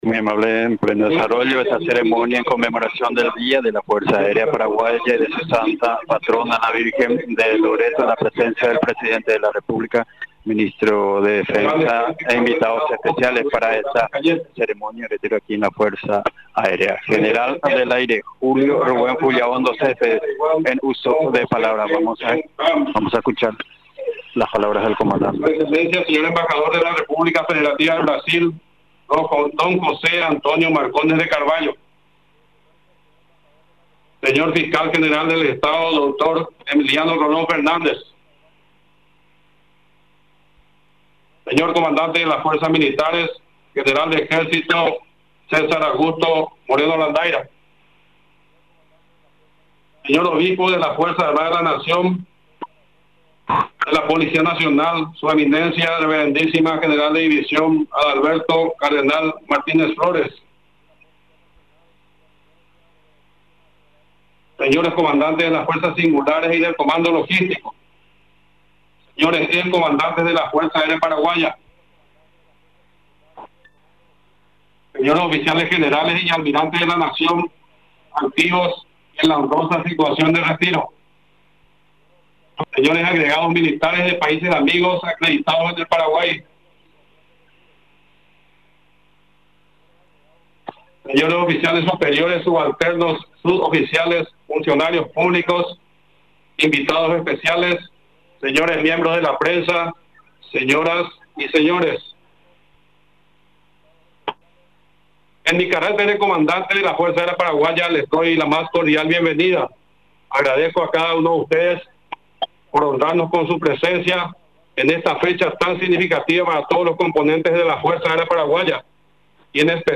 La ceremonia se realizó en la base aérea de Ñu Guasu, ubicada en la ciudad de Luque.